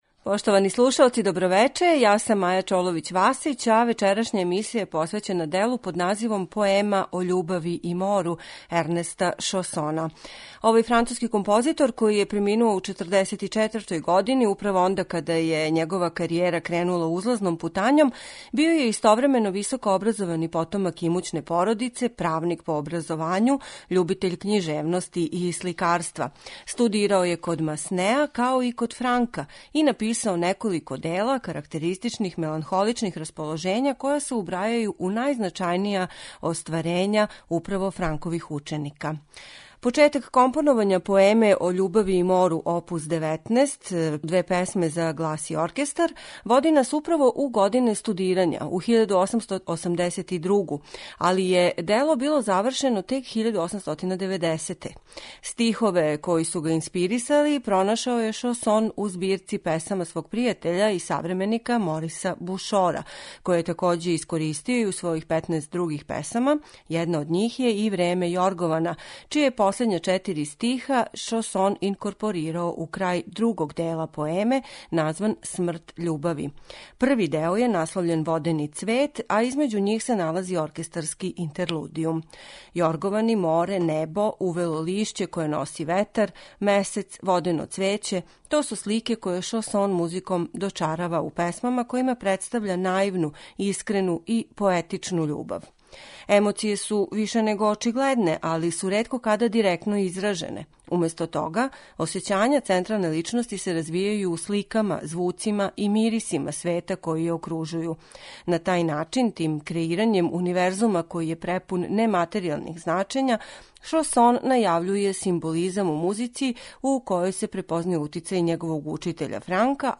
Поему о љубави и мору оп. 19, за глас и оркестар, у периоду од 1882. до 1890. године, на стихове Мориса Бушора, компоновао je Ернест Шосон.
мецосопрана
симфонијског оркестра